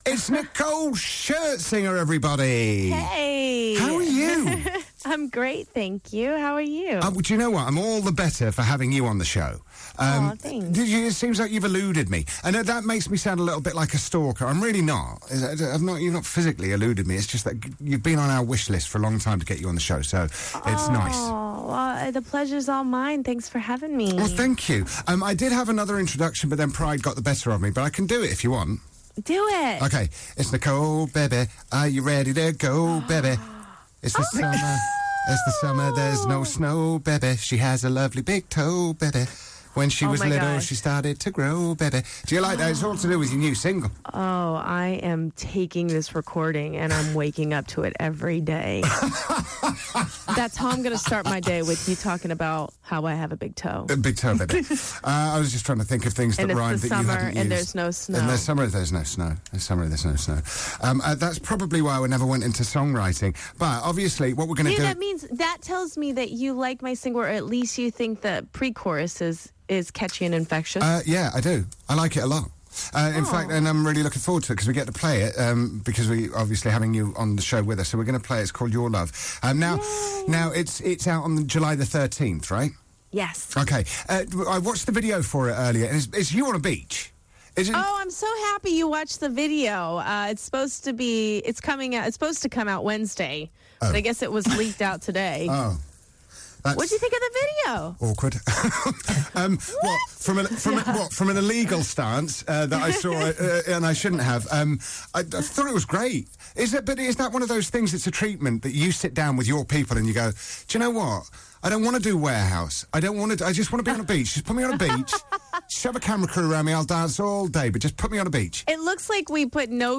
Nicole Scherzinger interview part 1